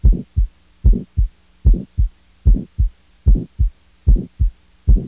Early-Systolic-Murmur.mp3